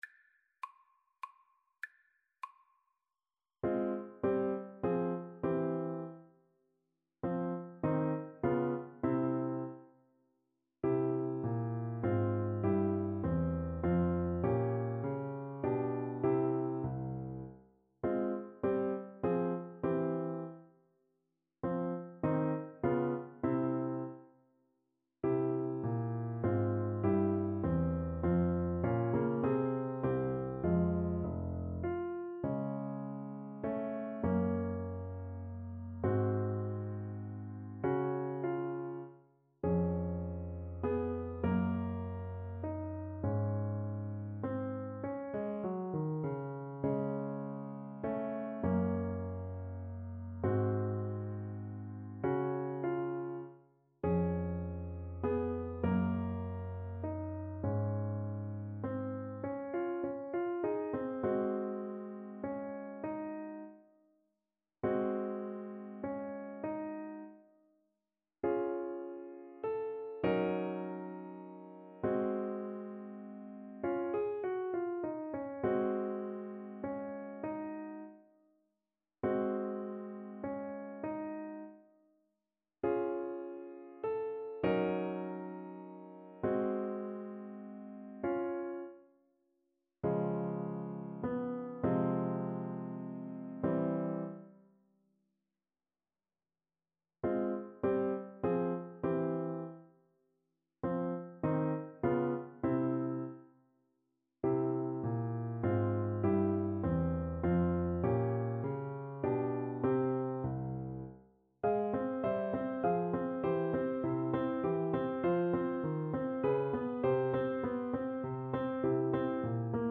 3/4 (View more 3/4 Music)
Allegretto
Classical (View more Classical French Horn Music)